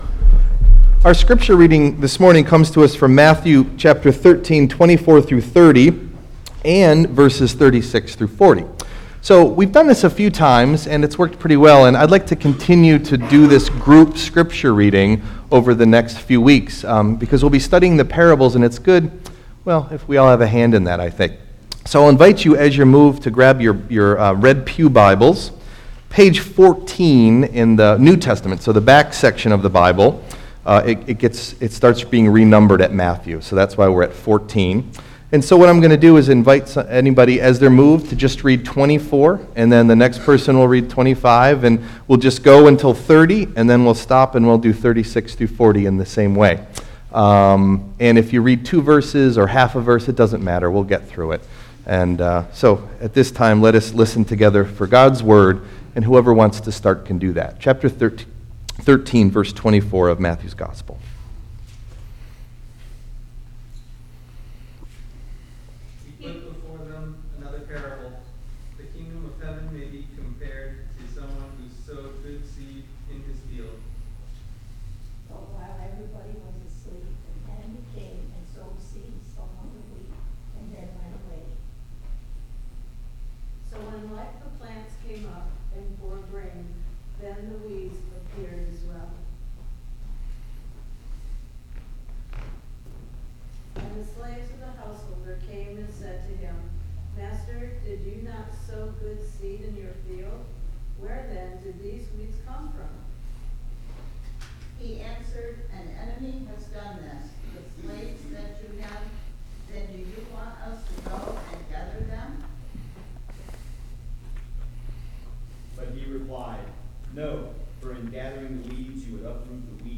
Date: January 11th, 2015 (Epiphany 1) Message Delivered at: The United Church of Underhill (UCC and UMC) Key Text(s): Mathew 13:24-30 Here is week 1 of a three part sermon series on the parables of Jesus. Today we look at the parable of the Weeds from Matthew 13.
Message Delivered at: The United Church of Underhill (UCC and UMC)